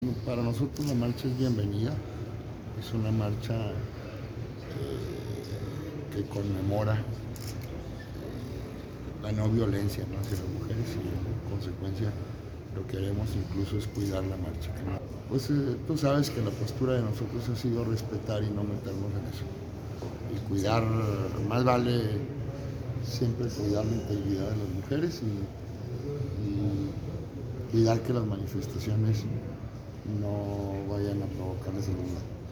Al respecto, el secretario General de Gobierno, César Jáuregui Moreno, aseguró que las autoridades desplegarán un operativo para cuidar la integridad de las manifestantes, no para interferir en su pronunciamiento.
Jauregui-sobre-marcha-contra-violencia-hacia-la-mujer.mp3